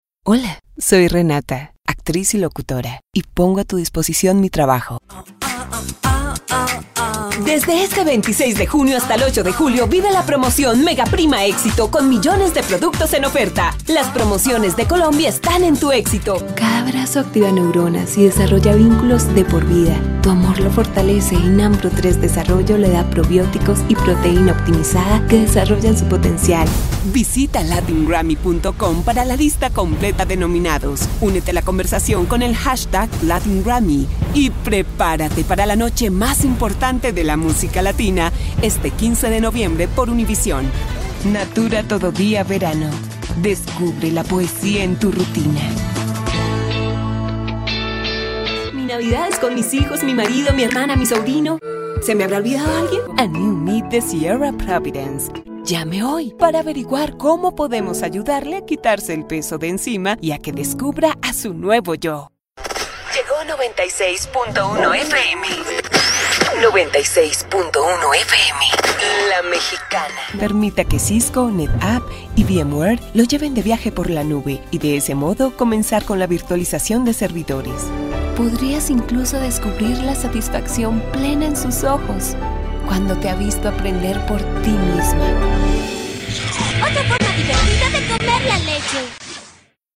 Showcase Demo
anti-announcer, confessional, conversational, genuine, real, sincere, storyteller
anti-announcer, caring, compelling, confident, conversational, friendly, genuine, inspirational, mature, real, sincere, sweet, warm
announcer, classy, promo, smooth
attitude, biting, political, serious